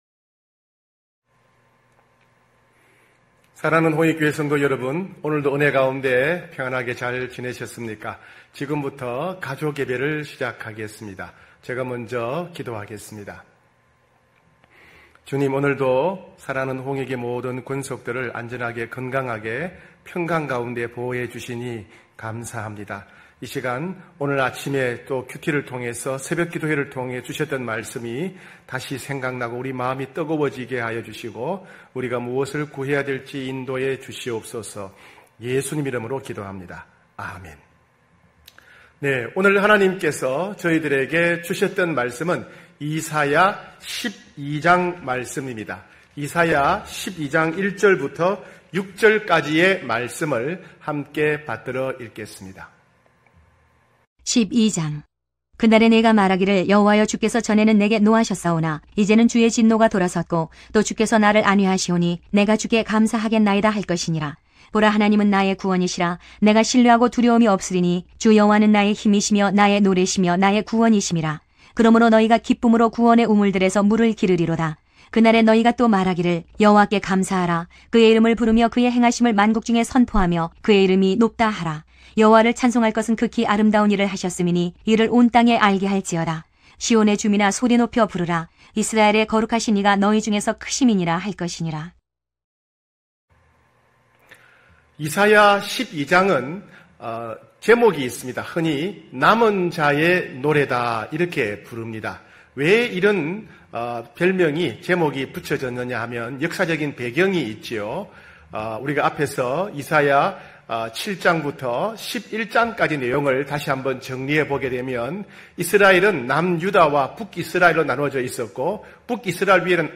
9시홍익가족예배(7월27일).mp3